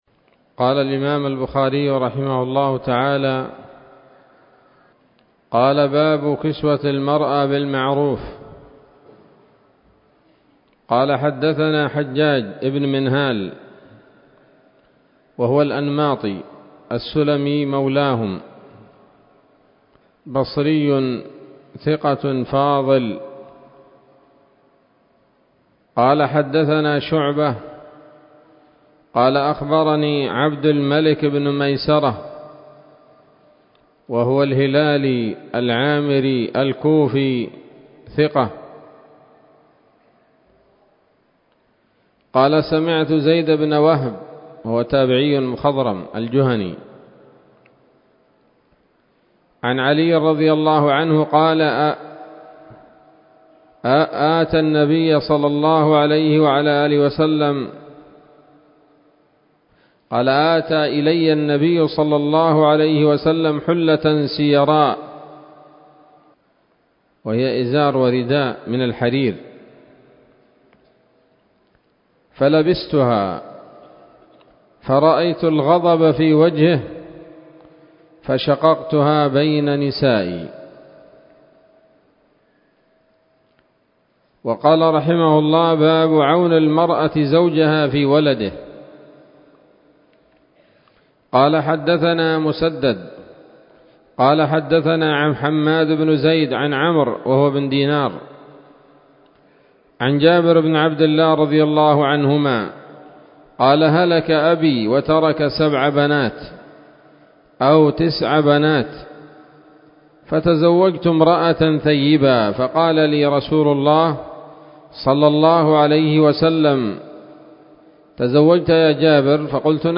الدروس العلمية شروح الحديث صحيح الإمام البخاري كتاب النفقات من صحيح البخاري
الدرس التاسع من كتاب النفقات من صحيح الإمام البخاري